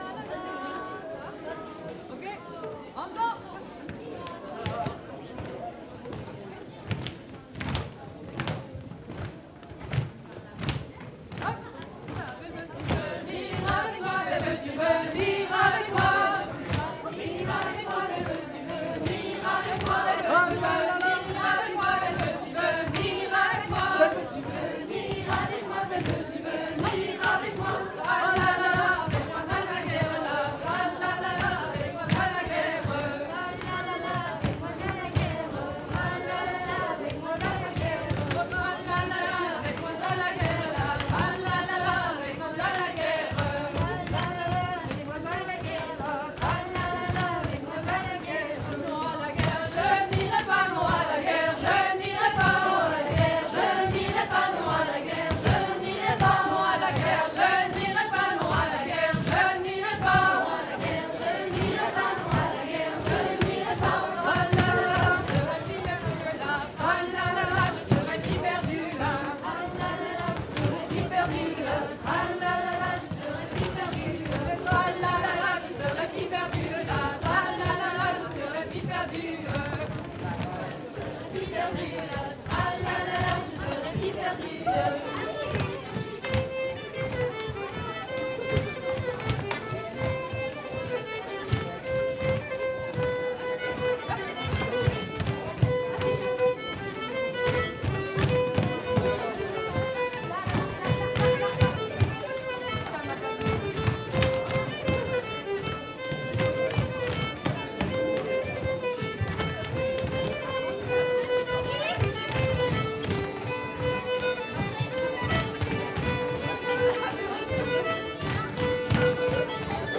03_rond_paludier-violons.mp3